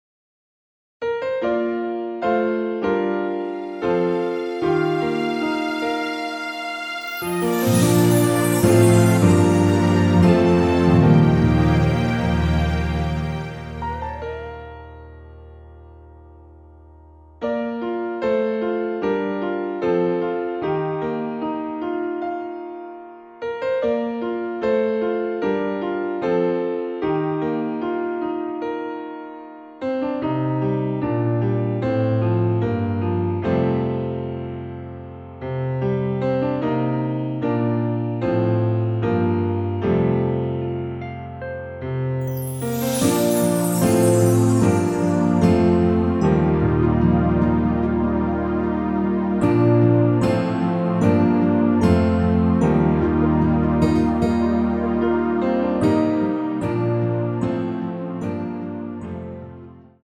내린 MR
Bb
◈ 곡명 옆 (-1)은 반음 내림, (+1)은 반음 올림 입니다.
앞부분30초, 뒷부분30초씩 편집해서 올려 드리고 있습니다.
중간에 음이 끈어지고 다시 나오는 이유는